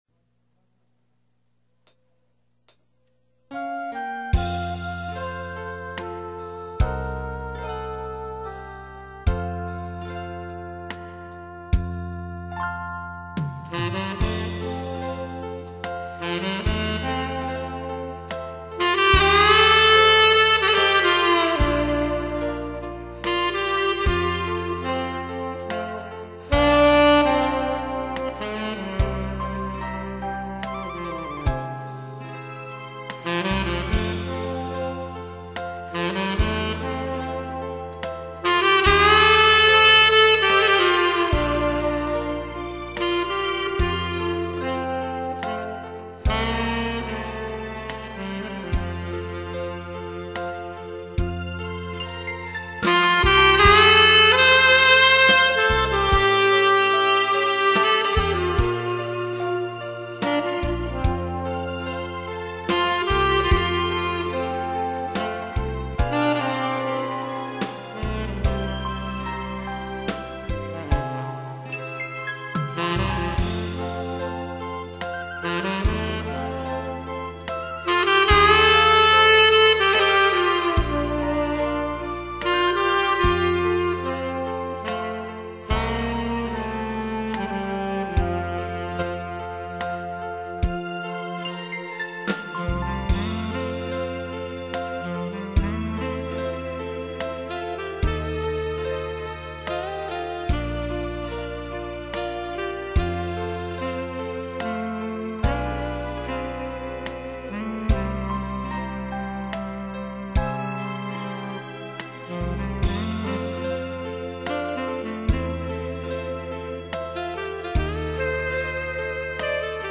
미국 올드 올드 팝송
테너연주